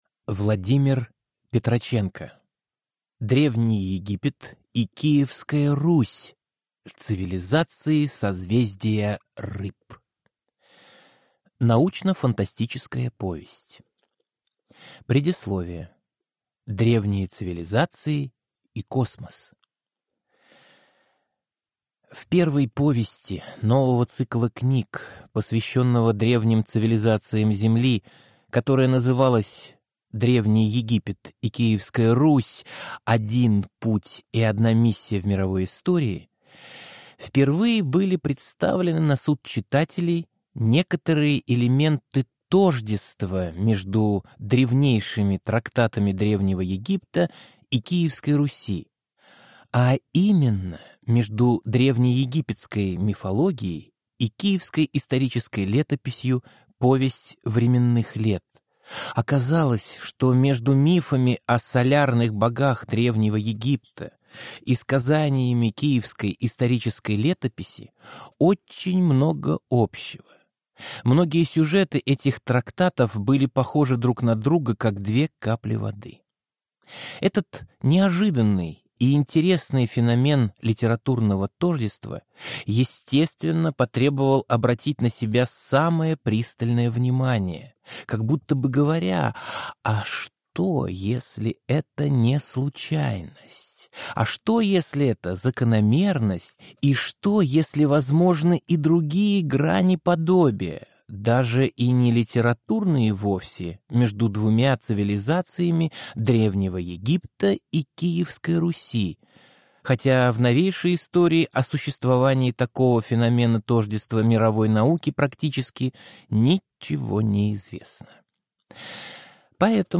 Аудиокнига Древний Египет и Киевская Русь – цивилизации созвездия Рыб | Библиотека аудиокниг